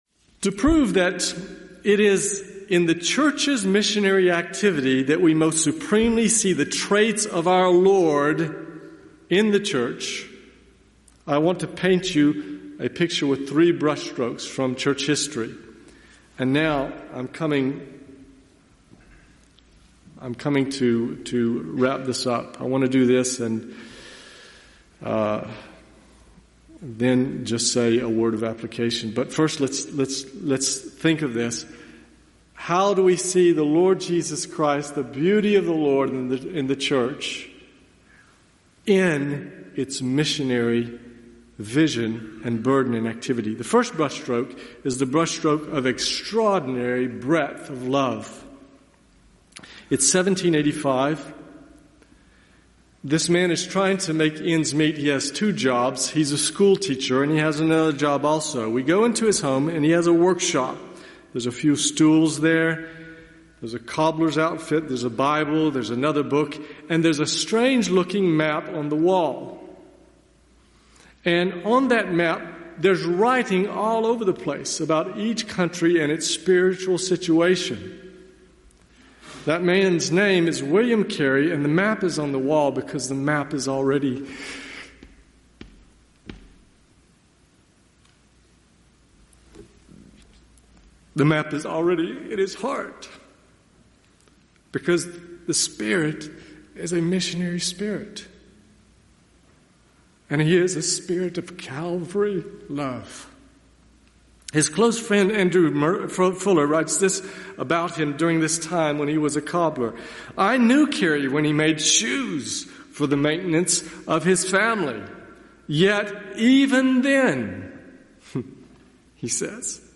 Category: Excerpts Topic: Missions